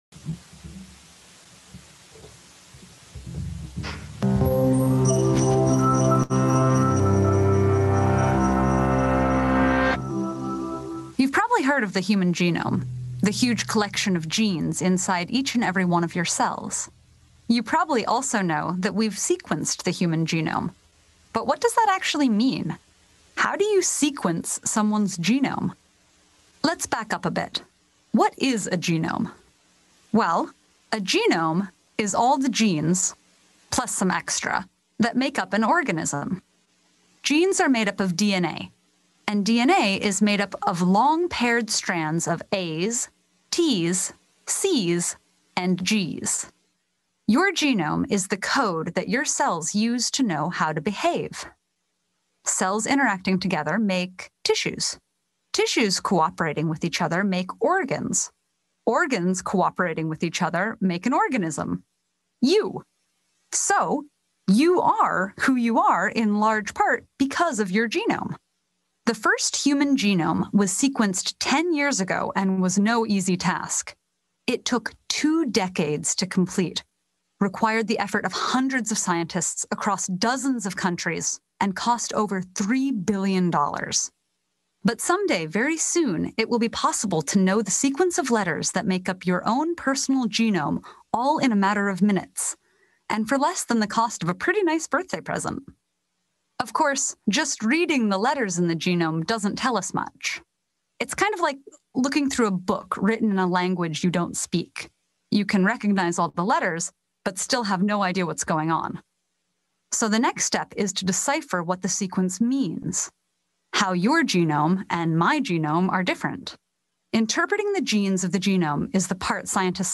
A message from the series "It's Not About Me."